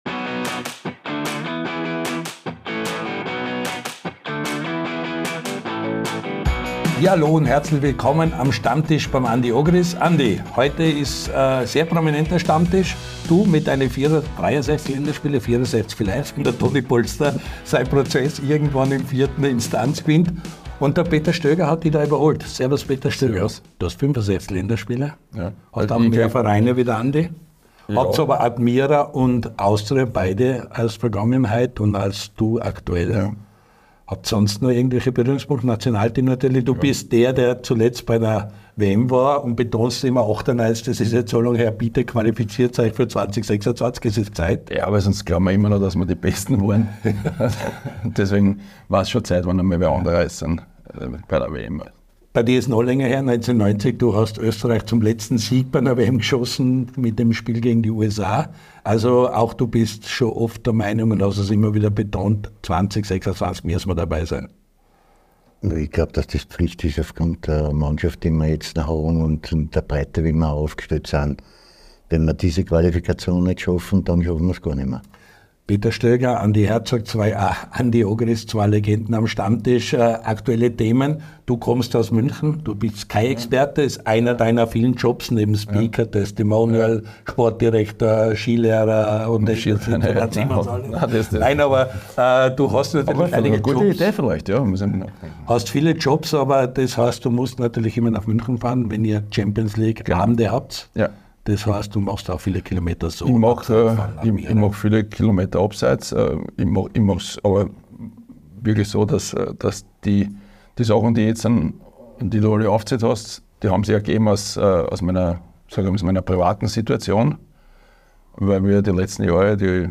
Admira Wacker startet als Tabellenführer der 2. Liga ins Frühjahr. Am Stammtisch bei Andy Ogris nennt Peter Stöger Ziele des Vereins und spricht über die Neuen: